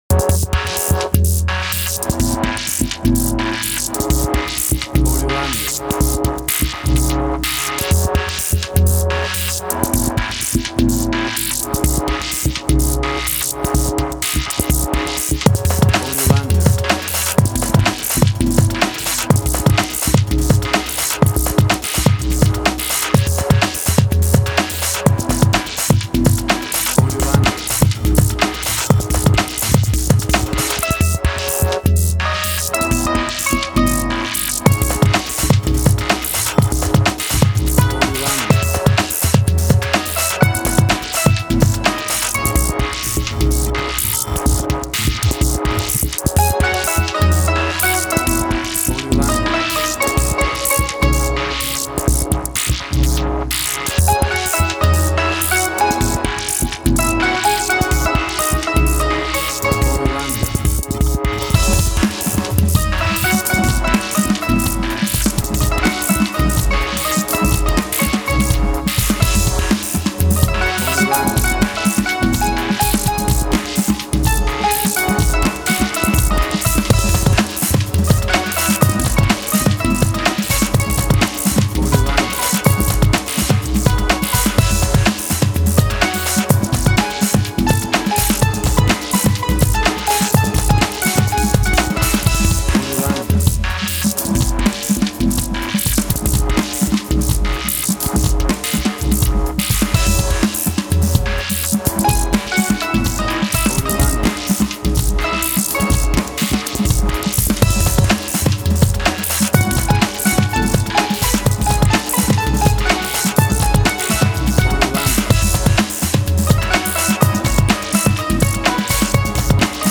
IDM, Glitch.
emotional music
Tempo (BPM): 126